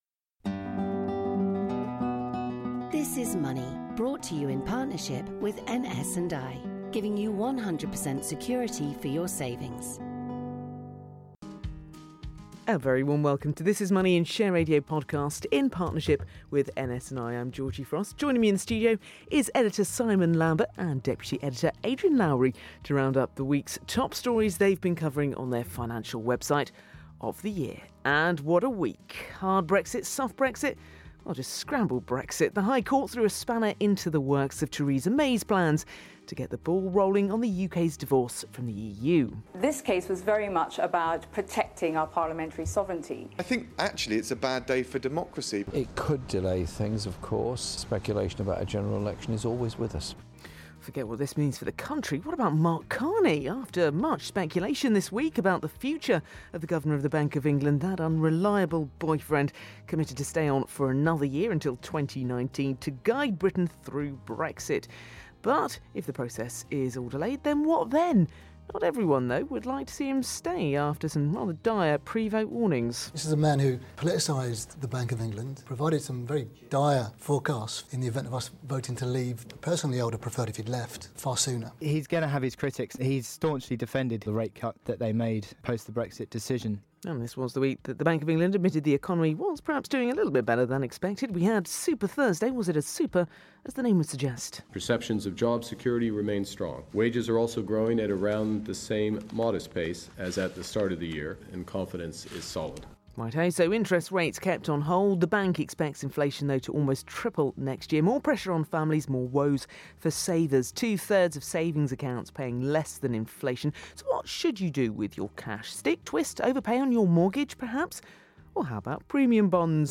Business News, Business, Investing, News